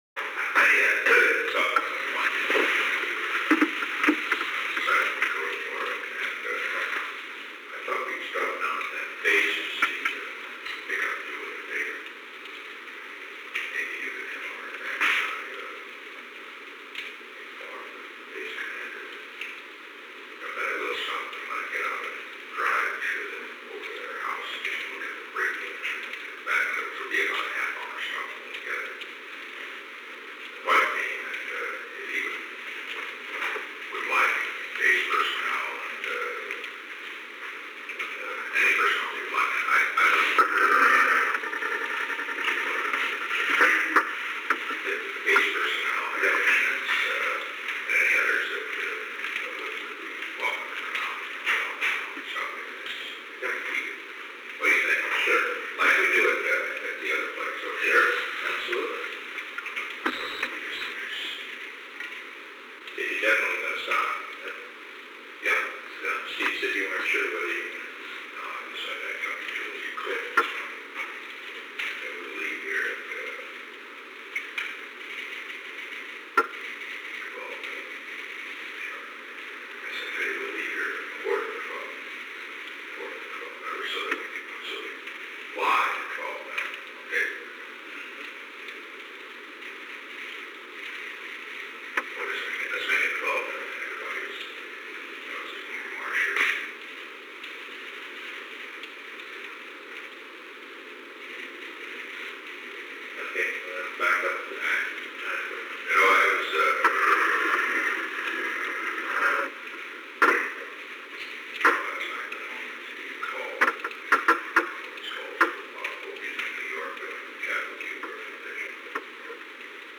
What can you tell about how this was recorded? The Oval Office taping system captured this recording, which is known as Conversation 858-002 of the White House Tapes.